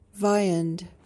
PRONUNCIATION: (VY-uhnd) MEANING: noun: 1.